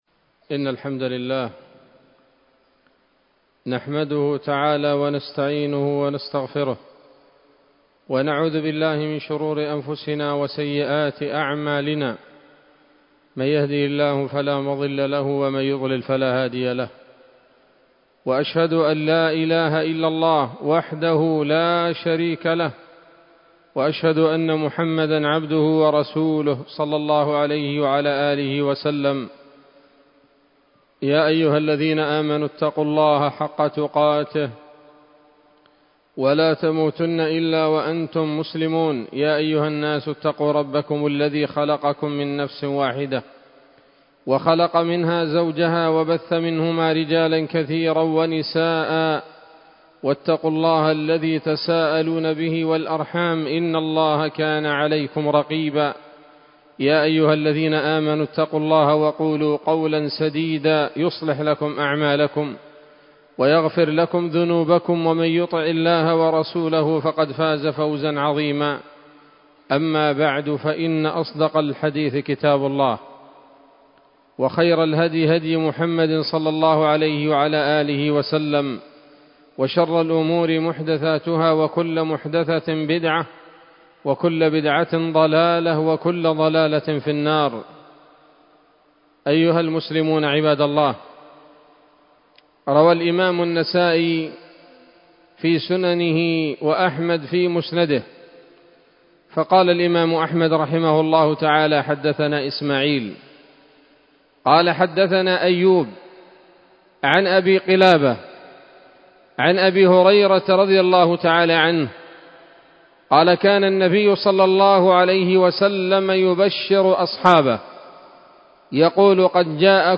خطبة جمعة بعنوان: (( مرحبا شهر البركات )) 29 شعبان 1446 هـ، دار الحديث السلفية بصلاح الدين